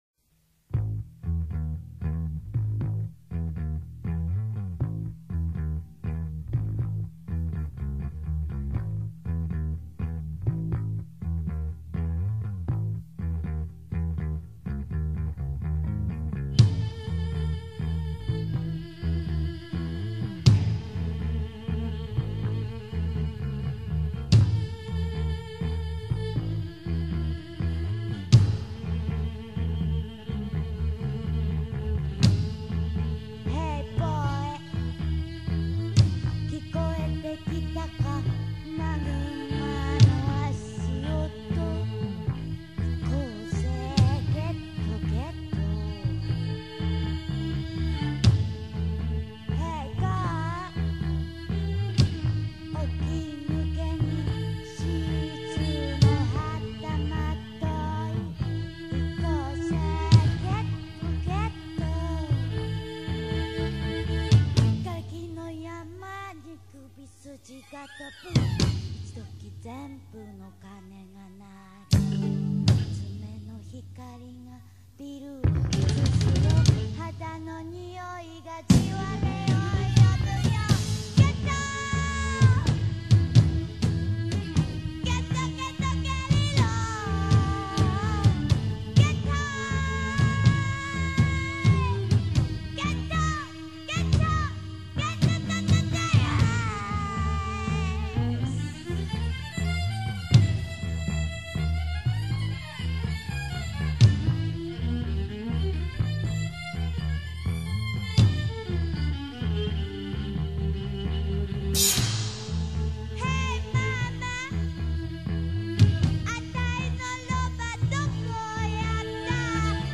Groupe de NoWave japonais Morceau de 1982